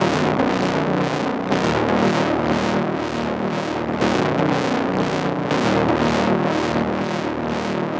TSNRG2 Bassline 022.wav